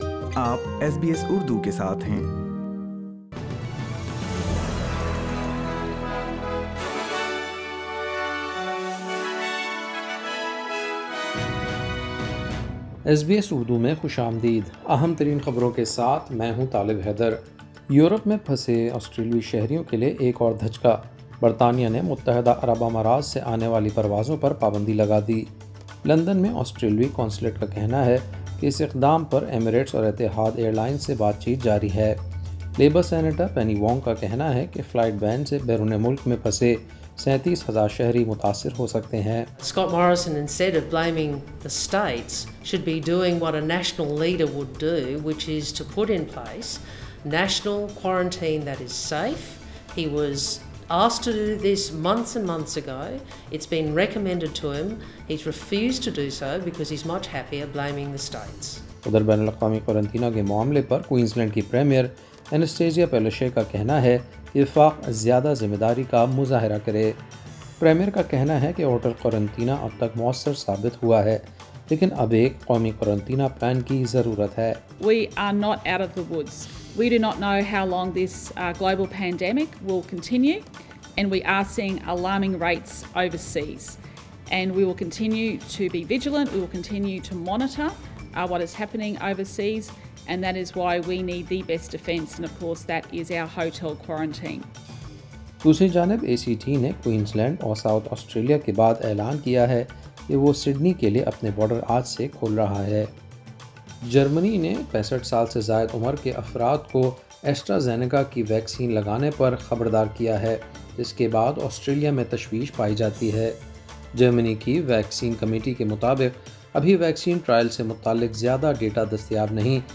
ایس بی ایس اردو خبریں 29 جنوری 2021